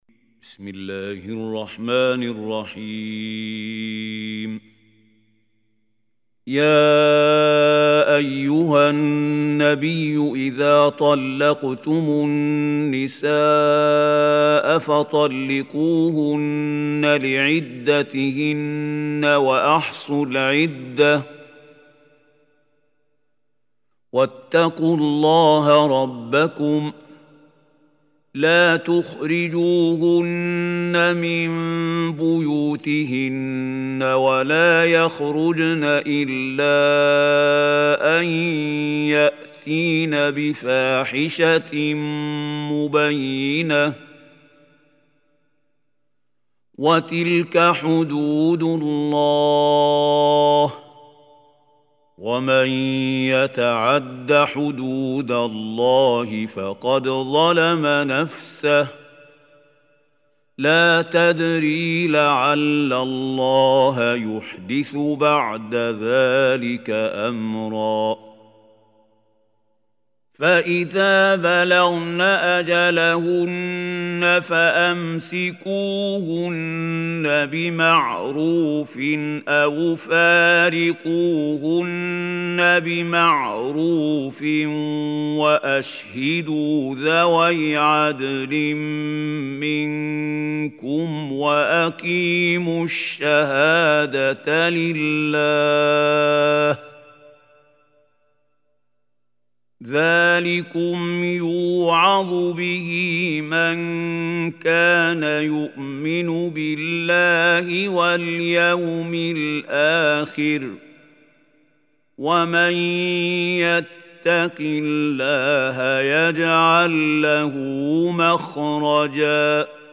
Surah Talaq Recitation by Muhammad Khalil Hussary
Surah Talaq is 65 surah of Holy Quran. Listen or play online mp3 tilawat / recitation of Surah Talaq in the beautiful voice of Sheikh Mahmoud Khalil Hussary.